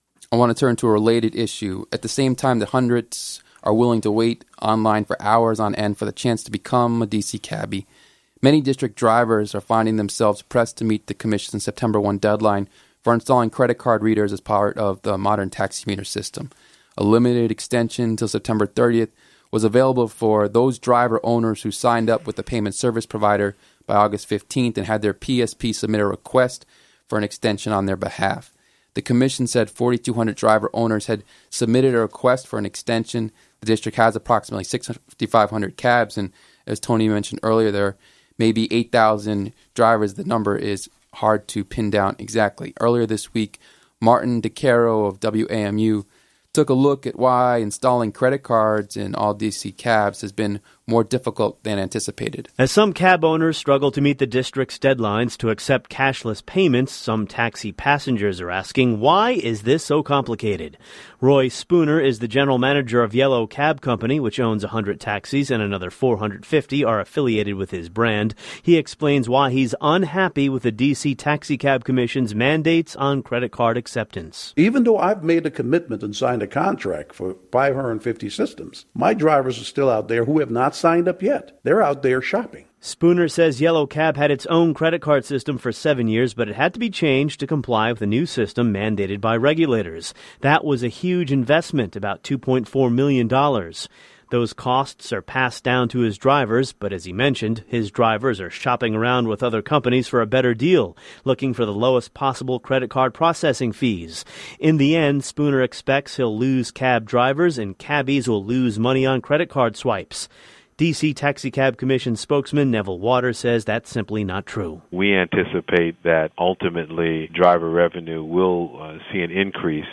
The Taxi Link airs on WUST 1120 AM.